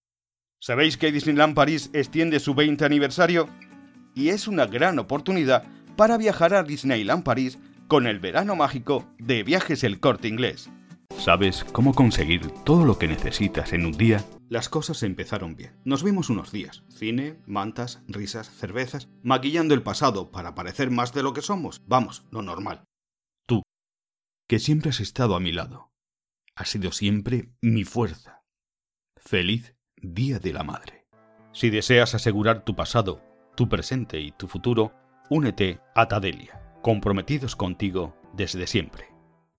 Sprechprobe: Sonstiges (Muttersprache):
I am an active professional speaker, native in Spanish, with my own studio.